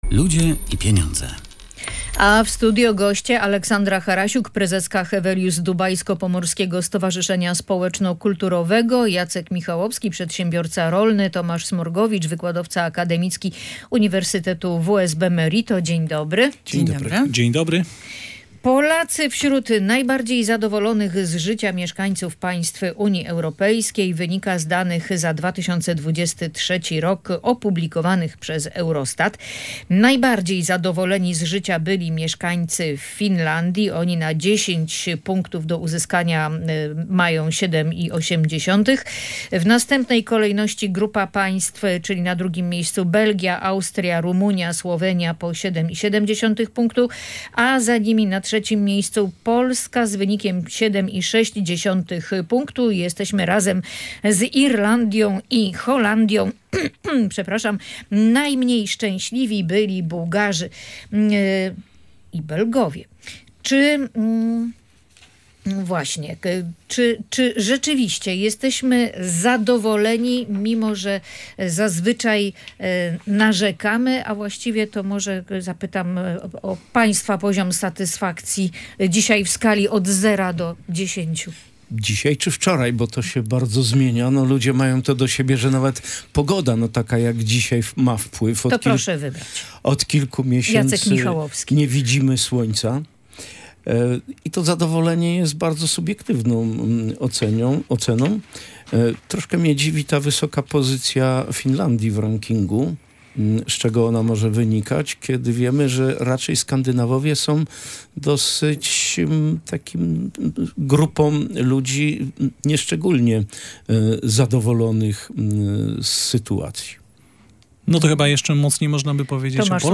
rozmawiała z gośćmi audycji „Ludzie i Pieniądze